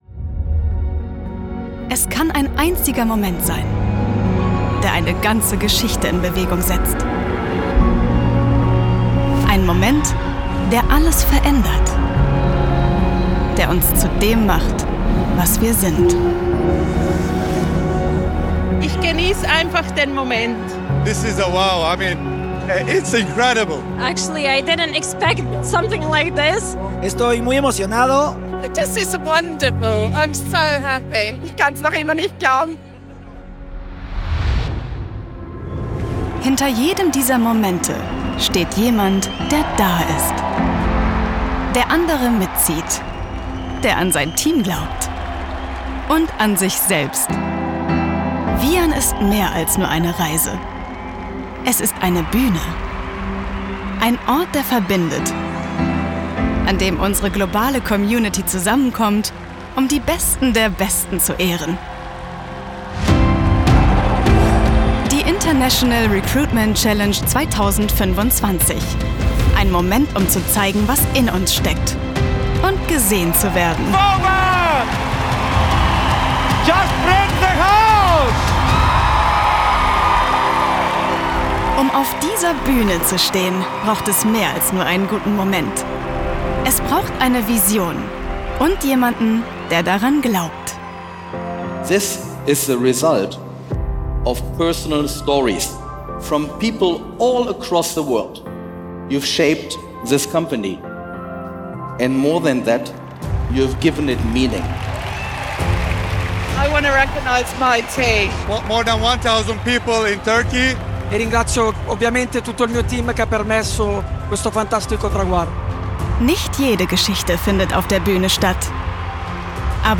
markant
Jung (18-30)
Norddeutsch
Narrative